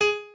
pianoadrib1_46.ogg